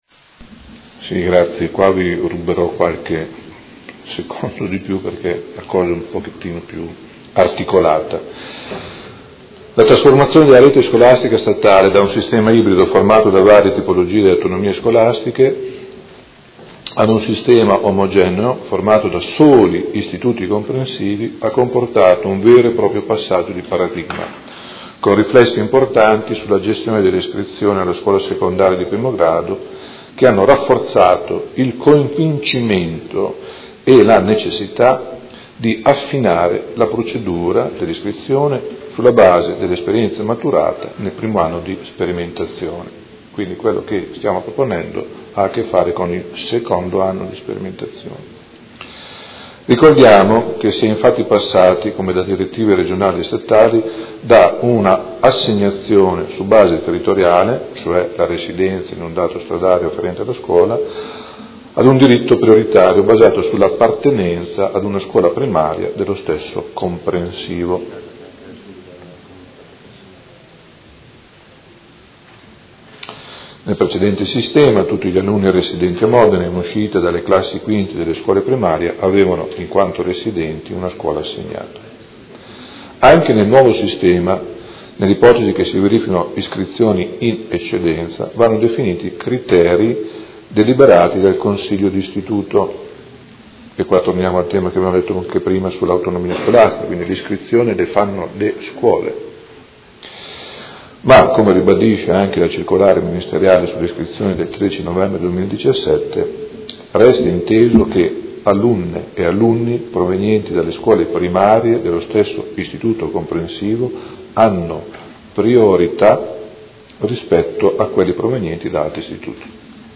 Seduta del 14/12/2017 Risponde. Interrogazione dei Consiglieri Baracchi e Carpentieri (PD) avente per oggetto: Protocollo iscrizione scuola secondarie di primo grado